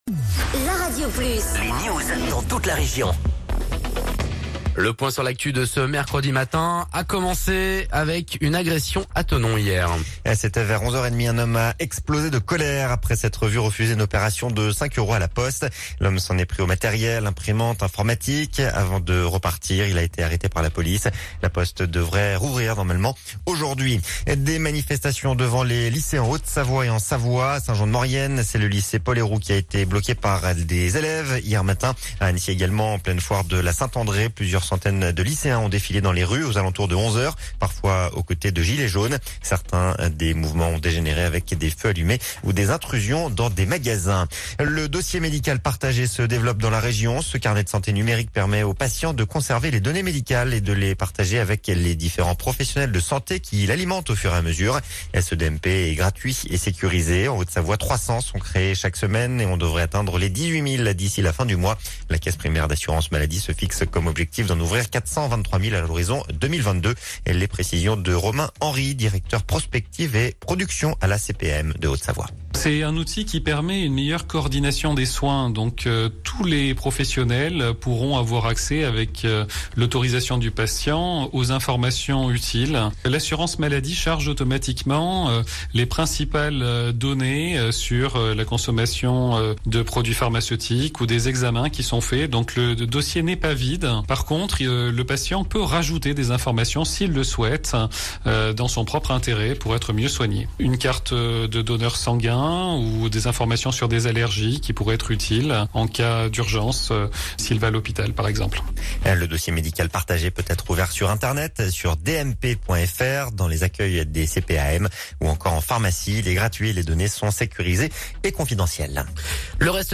Reportage sur la sécurité de la foire de la Saint-André à Annecy